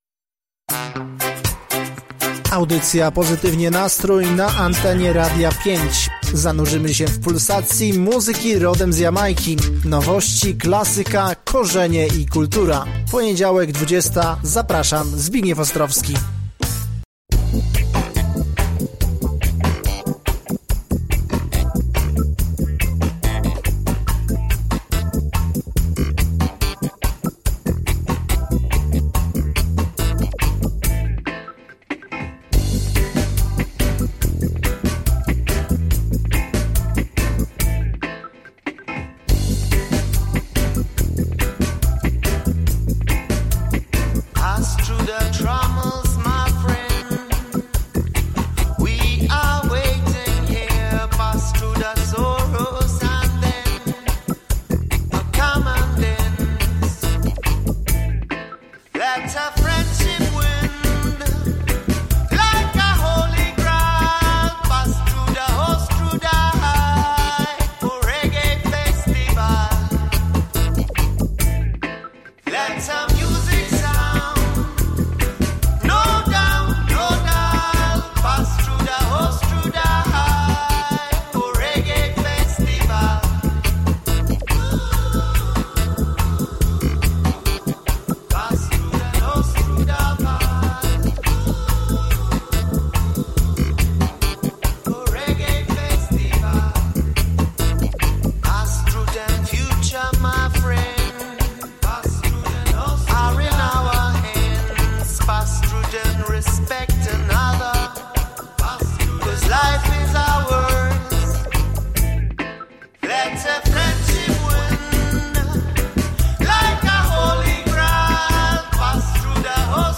Kolejna audycja z wywiadami nagranymi podczas Ostróda Reggae Festivalu.